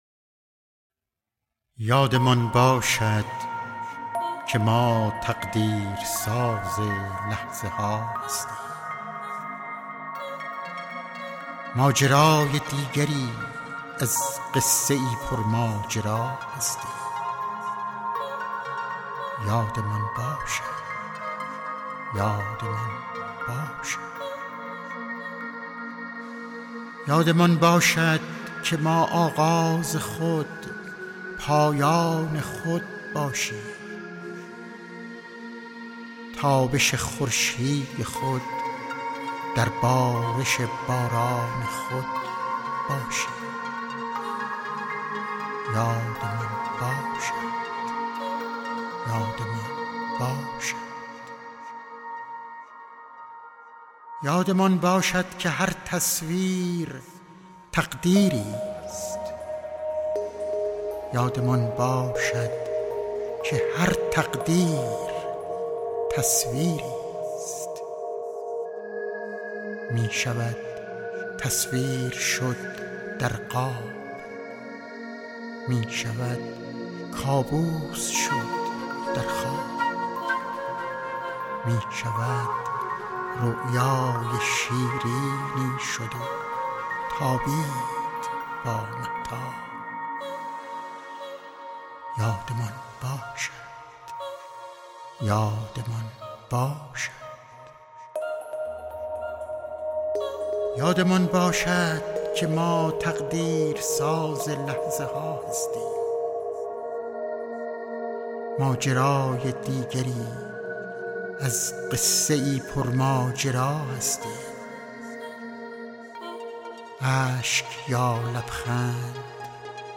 دانلود دکلمه یادمان باشد با صدای محمدعلی بهمنی